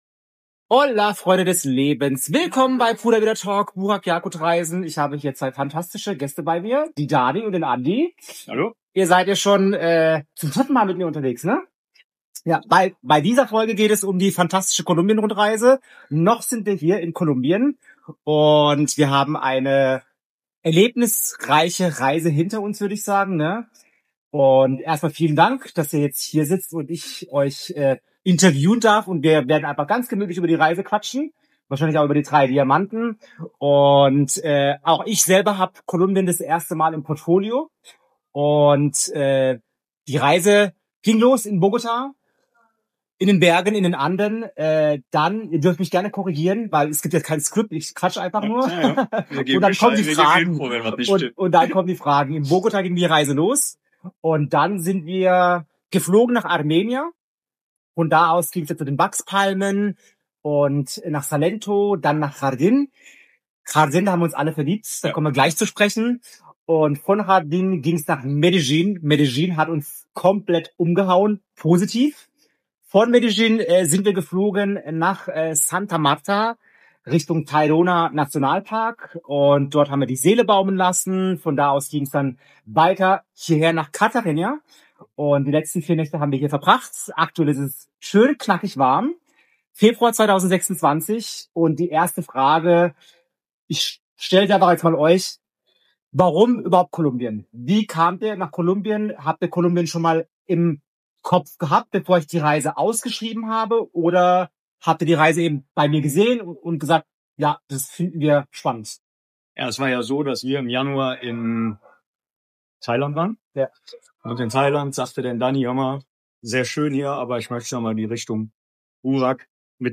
Es ist ein ehrliches und persönliches Gespräch über das Reisen, über neue Perspektiven und darüber, was entsteht, wenn wir uns auf das Unbekannte einlassen.